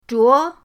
zhuo2.mp3